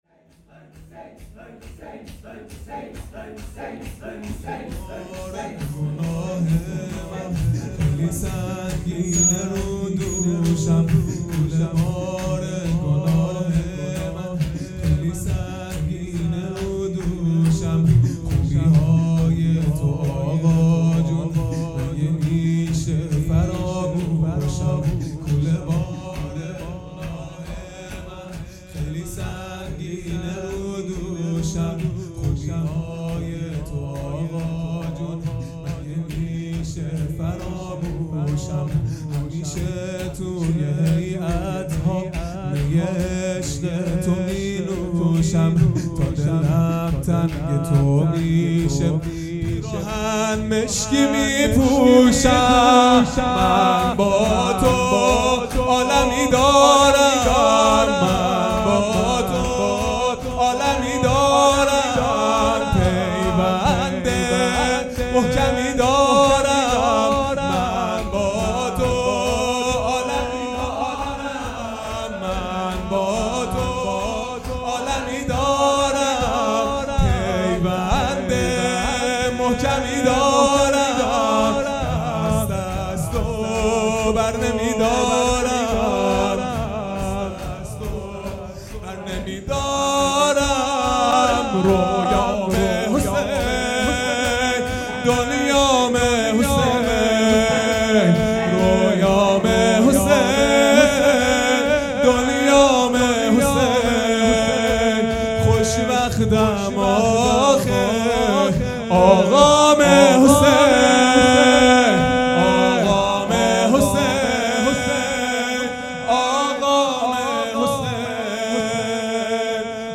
شور | کوله بار گناه من خیلی سنگینه رو دوشم
جلسه هفتگی | به مناسبت رحلت امام خمینی و قیام ۱۵ خرداد | ۱۵ خرداد ۱۳۹۹